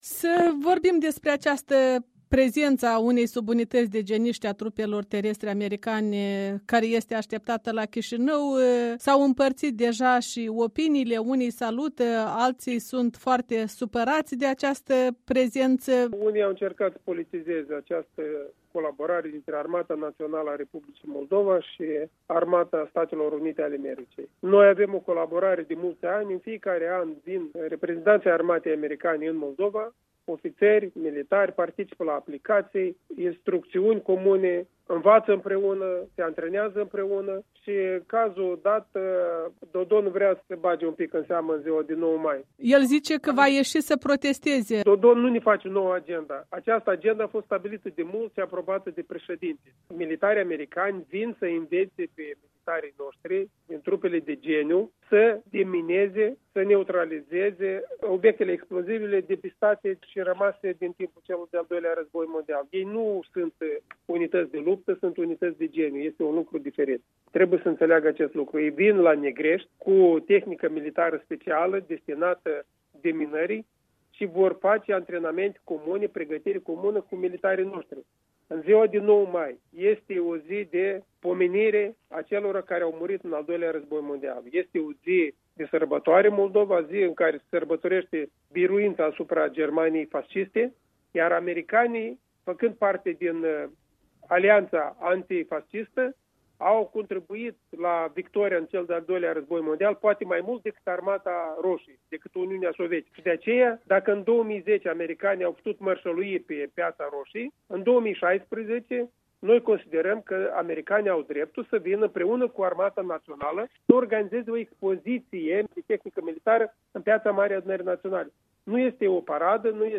Ministrul Apărărării în dialog la Radio Europa Liberă.